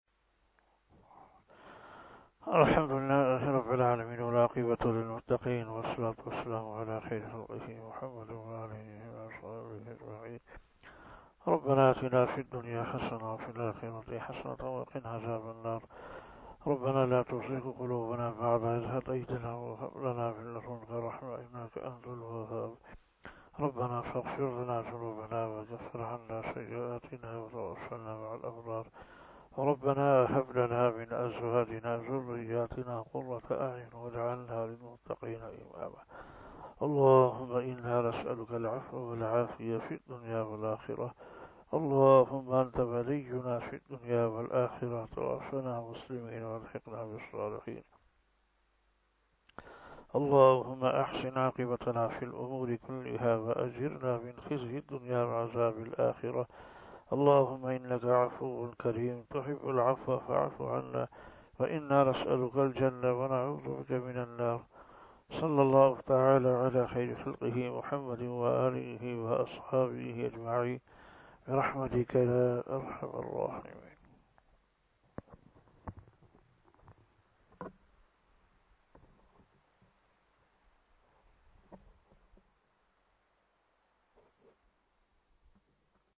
030 After Fajar Namaz Bayan 07 April 2022 ( 05 Ramadan 1443HJ) Thursday